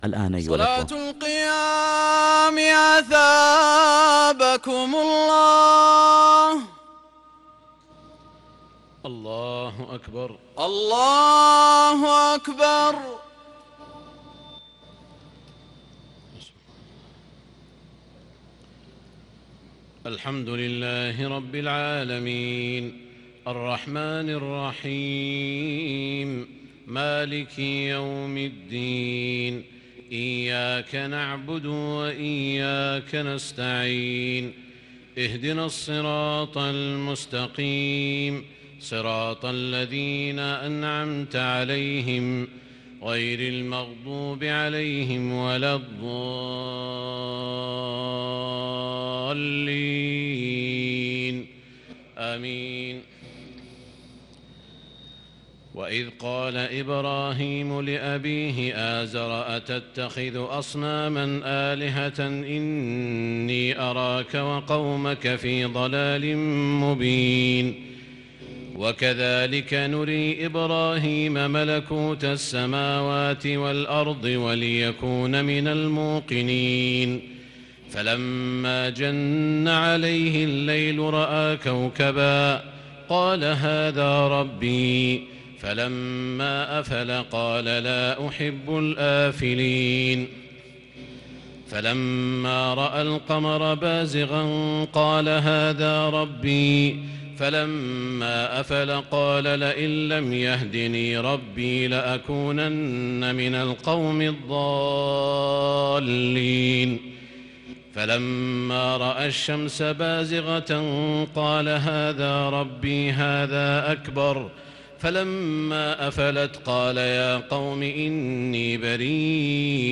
تراويح ليلة 10 رمضان 1441هـ من سورة الأنعام (74-135) |taraweeh 10st niqht ramadan Surah Al-Anaam1441H > تراويح الحرم المكي عام 1441 🕋 > التراويح - تلاوات الحرمين